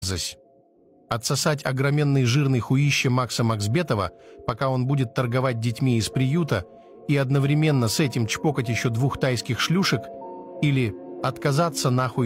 chto ty vyberesh Meme Sound Effect